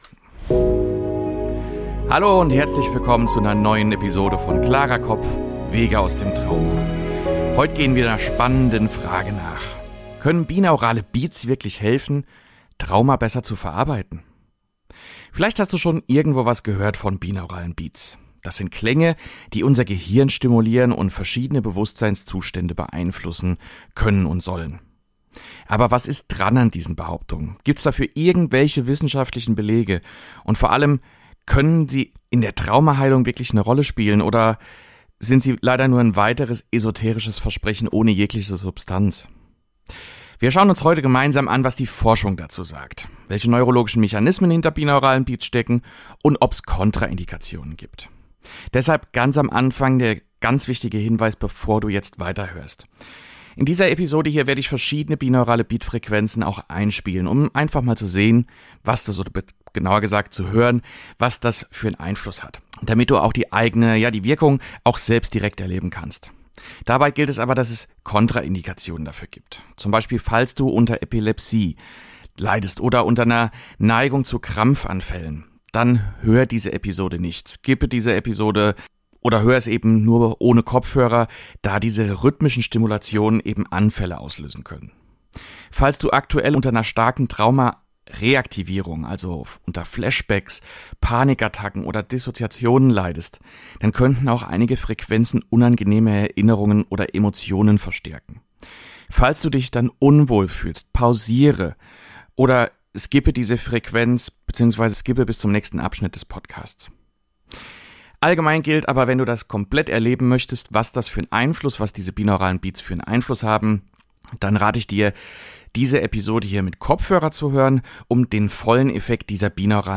In dieser Folge tauchen wir tief in die Wissenschaft ein und testen verschiedene Frequenzen gemeinsam.